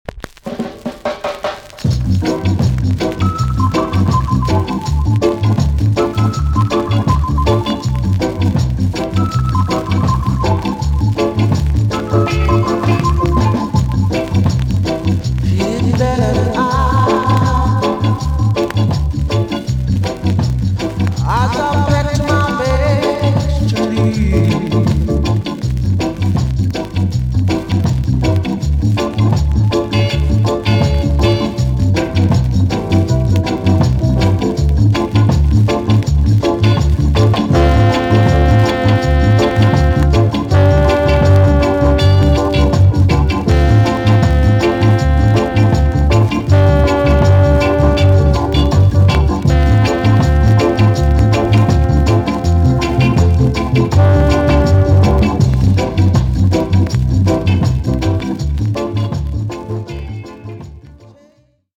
TOP >SKA & ROCKSTEADY
B.SIDE Version
VG+ 少し軽いチリノイズがありますが良好です。